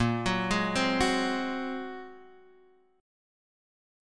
A#7sus2#5 Chord